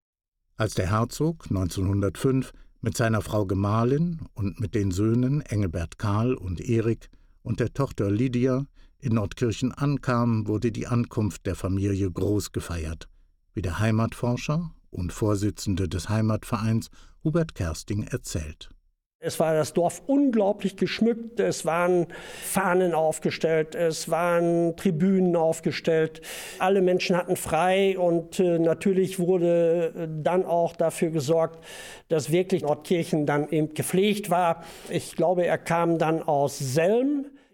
Interviews vor Ort